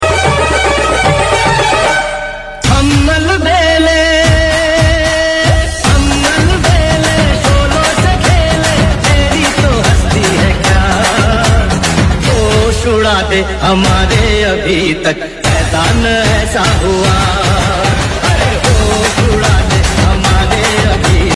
A Duet of Legend